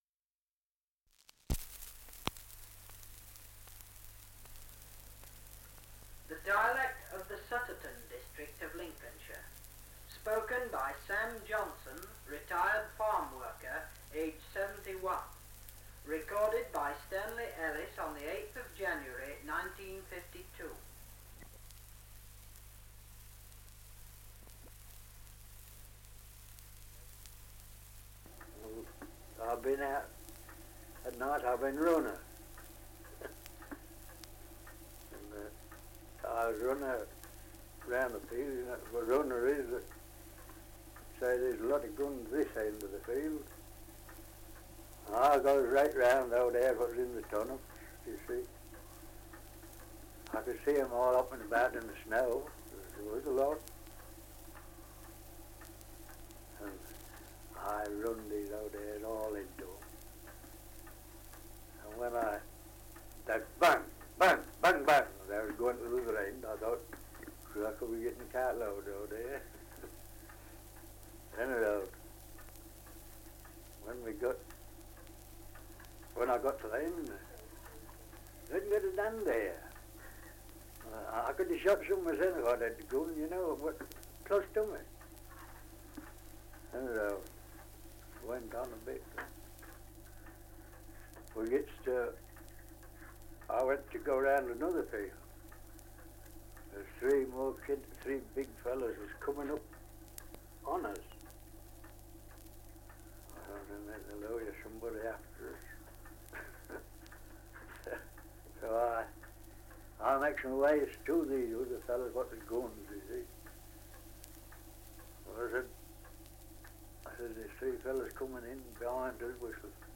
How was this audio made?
Survey of English Dialects recording in Sutterton, Lincolnshire 78 r.p.m., cellulose nitrate on aluminium